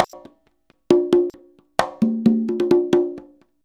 133CONGA02-R.wav